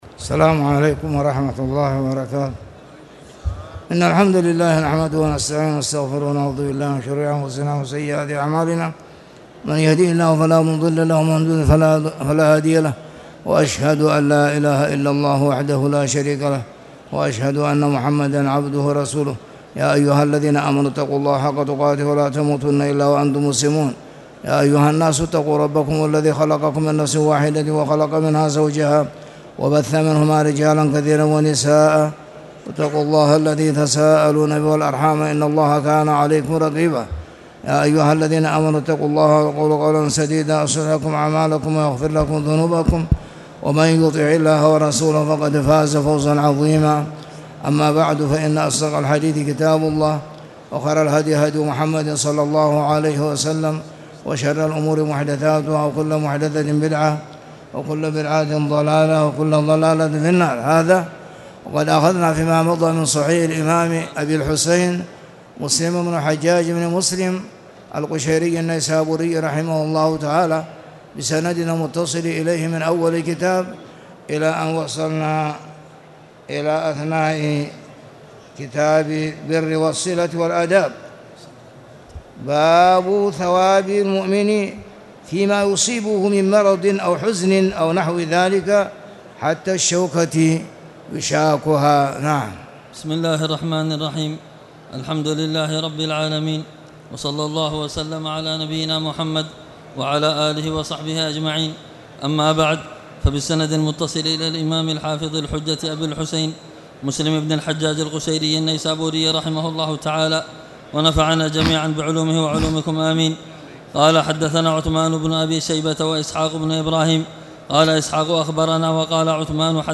تاريخ النشر ١٨ ربيع الثاني ١٤٣٨ هـ المكان: المسجد الحرام الشيخ